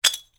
gren_pin.wav